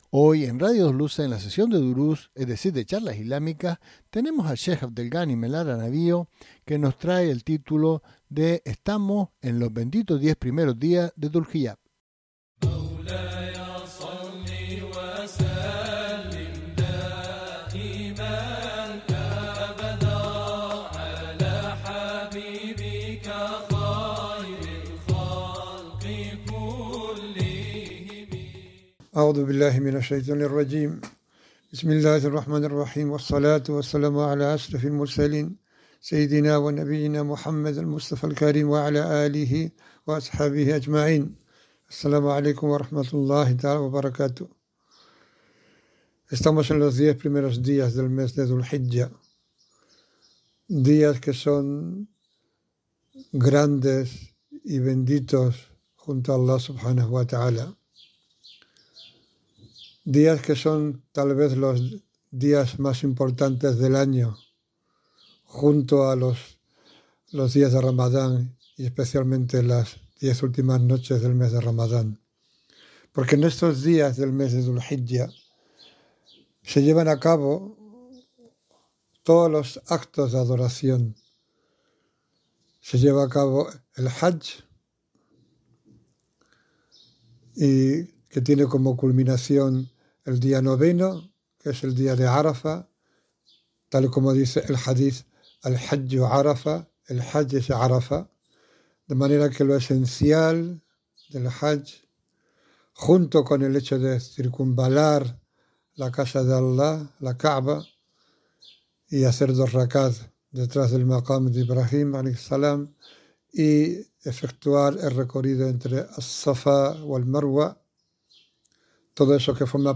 Charla